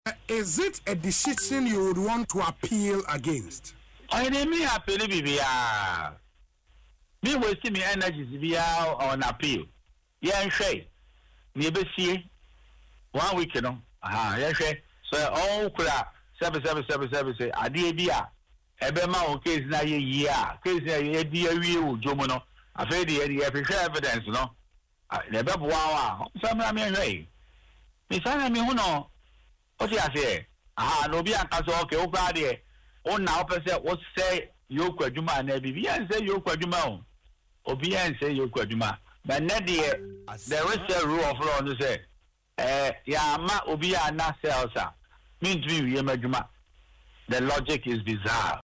In an interview on Asempa FM’s Ekosii Sen, he criticised the circumstances surrounding the detention.